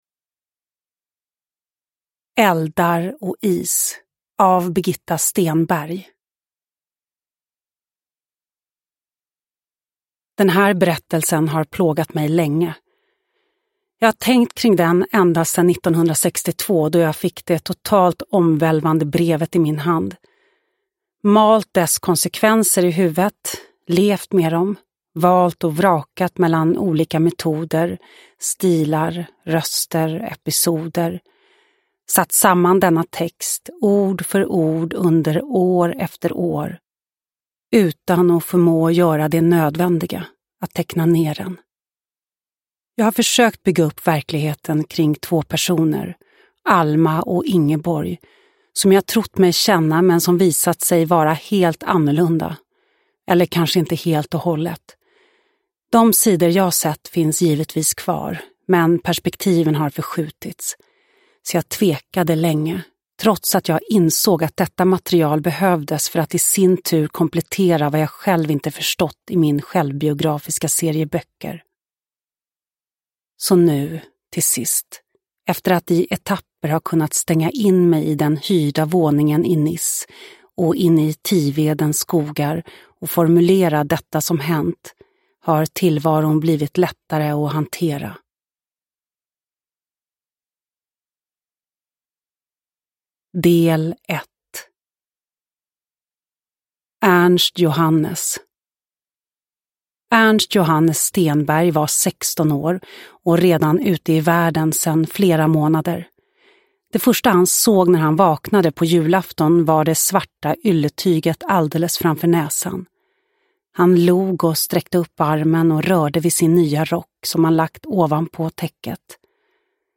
Eldar och is – Ljudbok
Uppläsare: Lo Kauppi